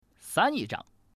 Index of /qixiGame/release/guanDan/jsGuangDian/assets/res/zhuandan/sound/woman/